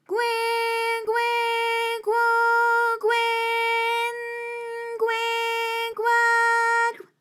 ALYS-DB-001-JPN - First Japanese UTAU vocal library of ALYS.
gwe_gwe_gwo_gwe_n_gwe_gwa_gw.wav